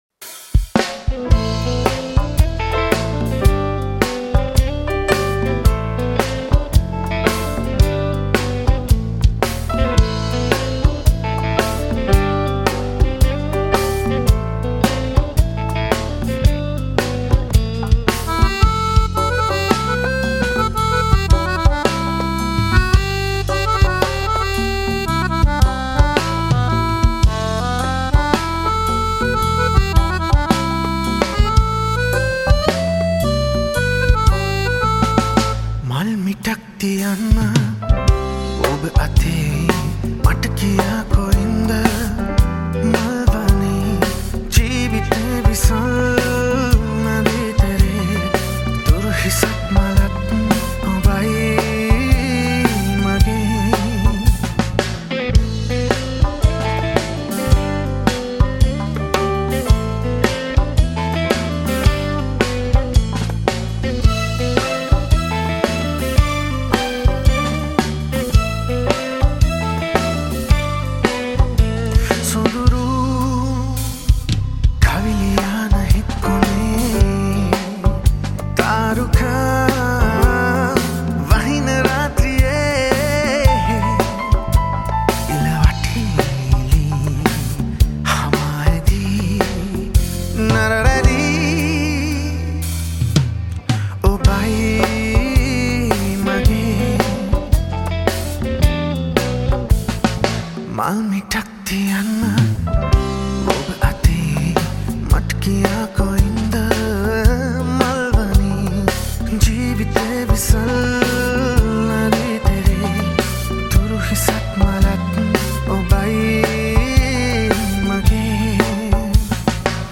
live songs acoustic songs
sinhala cover songs